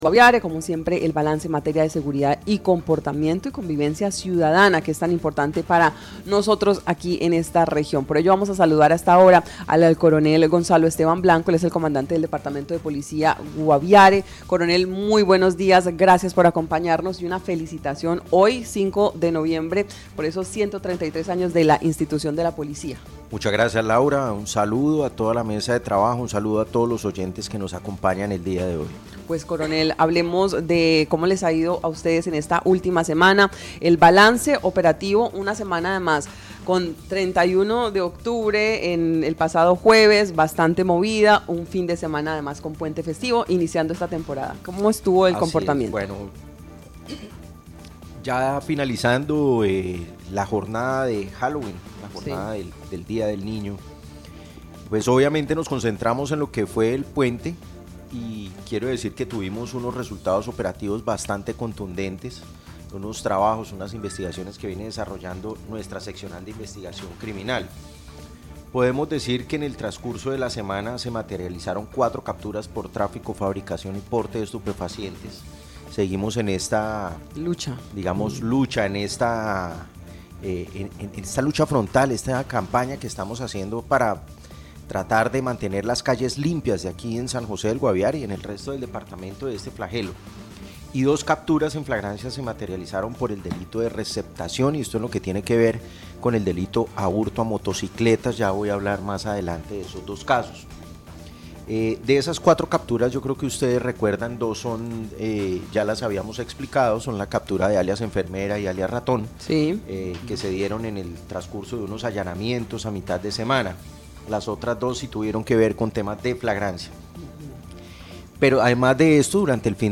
El Coronel Gonzalo Esteban Blanco, comandante del Departamento de Policía Guaviare, presentó el balance semanal de seguridad.